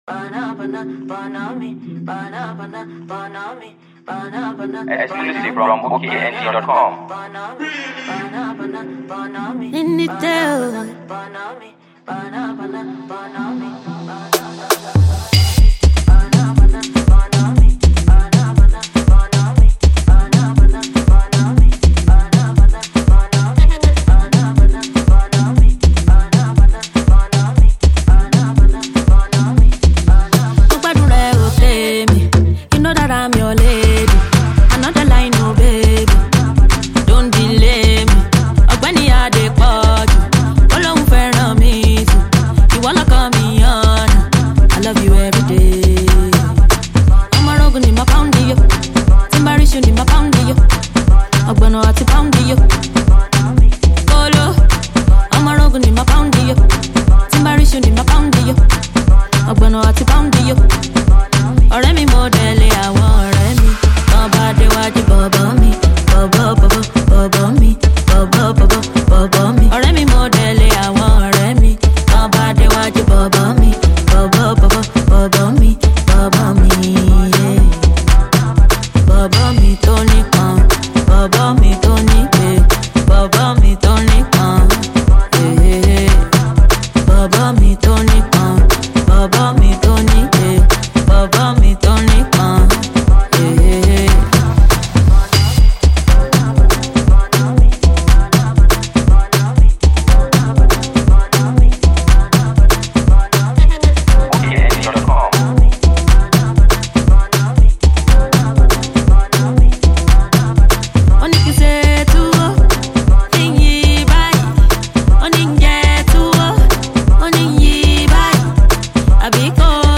AfroHouse